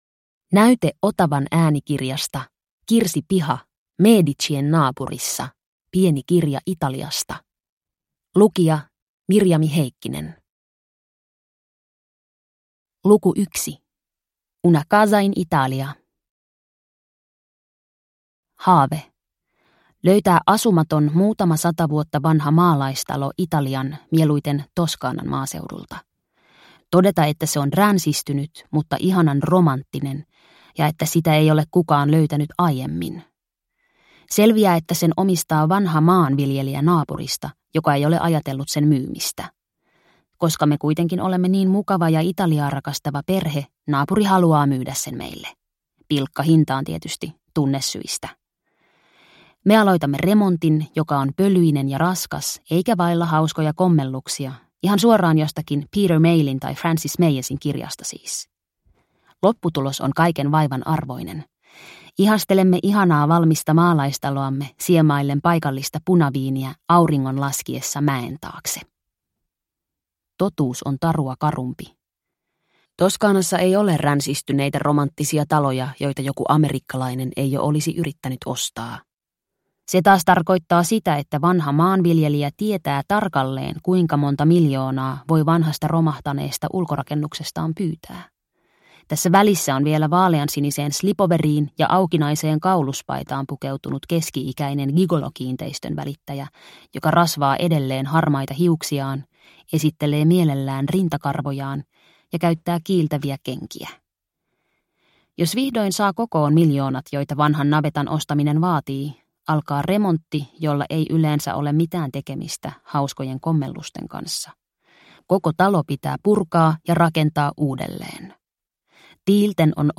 Medicien naapurissa – Ljudbok